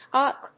/pˈʌk(米国英語)/